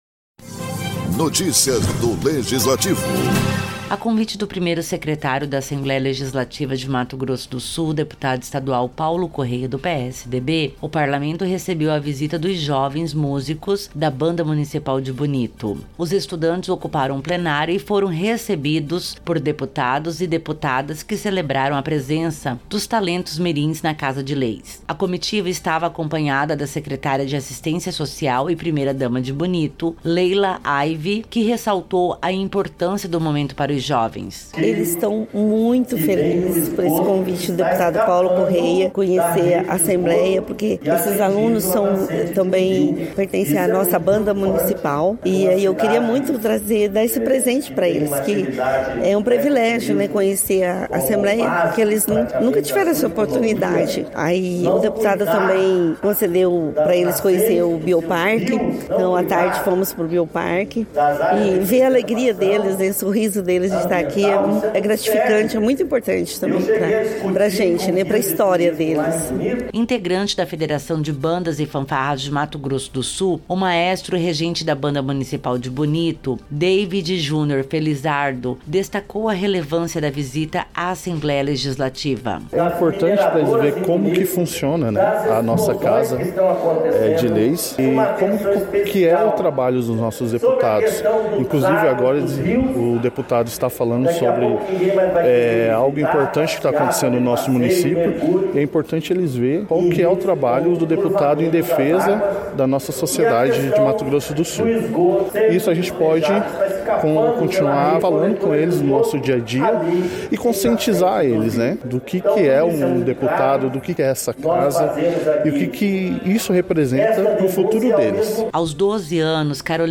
Os estudantes ocuparam o plenário e foram calorosamente acolhidos por deputados e deputadas, que celebraram a presença dos talentos mirins na Casa de Leis.
Produção e Locução